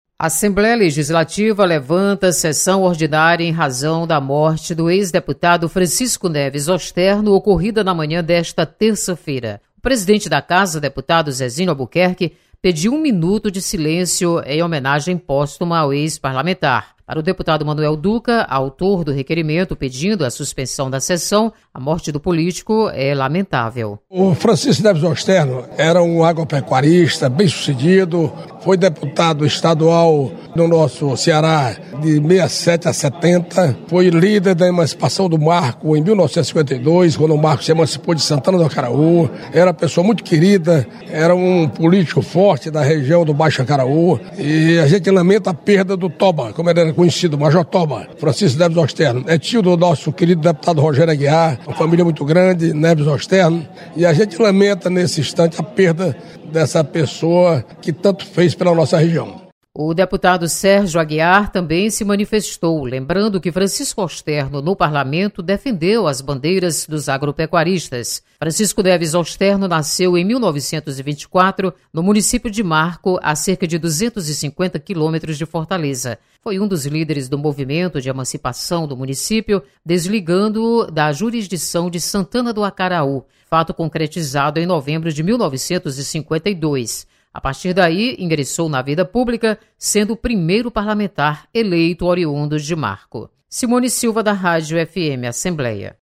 Deputados prestam homenagem ao ex-deputado Francisco Neves Osterno. Repórter